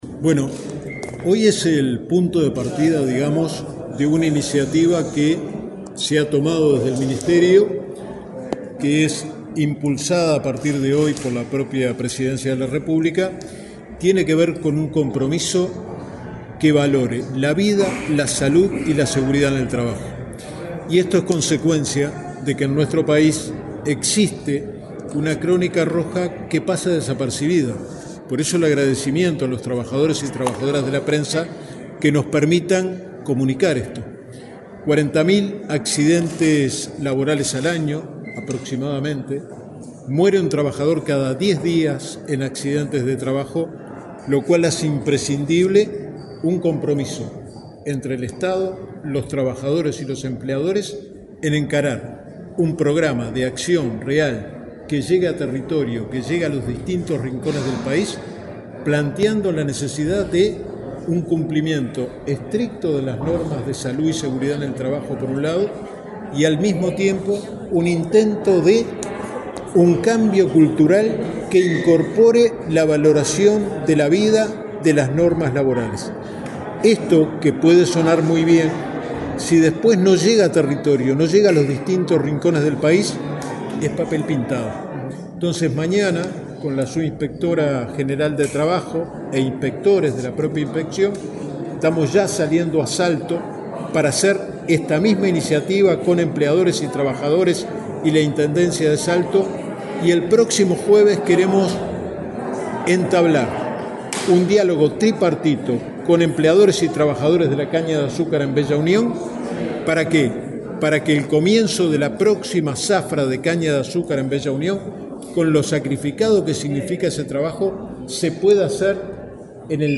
Declaraciones del inspector general del Trabajo y Seguridad Social, Luis Puig 01/04/2025 Compartir Facebook X Copiar enlace WhatsApp LinkedIn El inspector general del Trabajo y Seguridad Social, Luis Puig, dialogó con la prensa en la Torre Ejecutiva, luego de que la cartera ministerial que integra presentara el Compromiso Nacional por la Vida, la Salud y la Seguridad en el Trabajo.